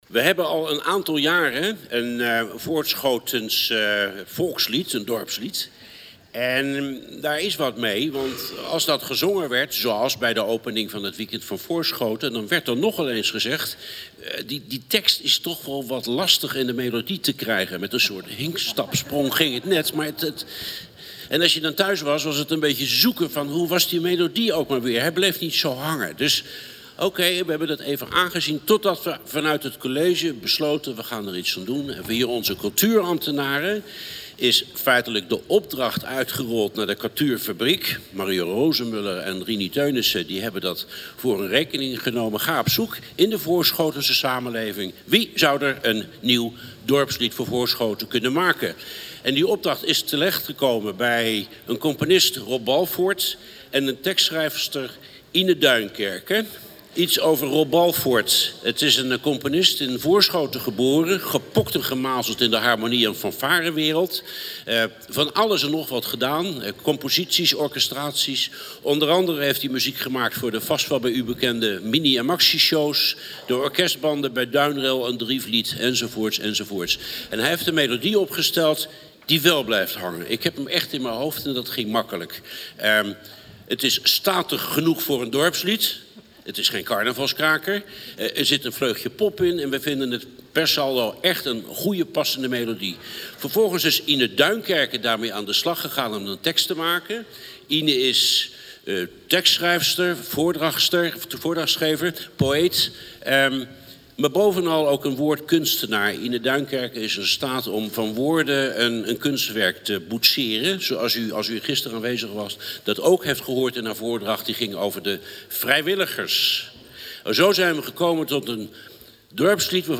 De officiële opening van het Weekend van Voorschoten, afgelopen zaterdag, was een mooie gelegenheid om het nieuwe dorpslied van Voorschoten voor de eerste keer uit te voeren.
Zij maakten, in de woorden van Van der Elst, een lied dat “…statig genoeg is voor een dorpslied.
AUDIO: Gedeelten uit de inleiding van wethouder Hans van der Elst en een gedeelte uit de eerste uitvoering van ‘Parel aan de Vliet.’